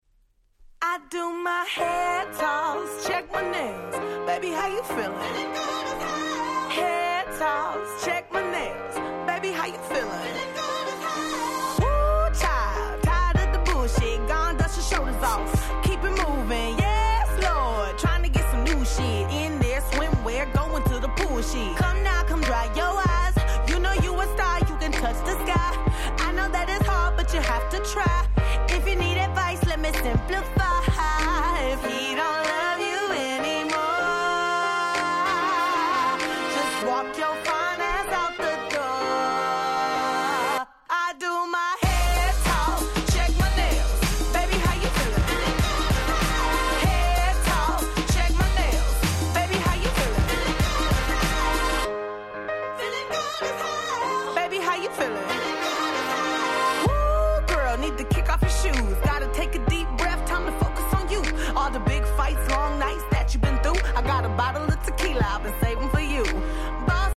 19' Smash Hit R&B !!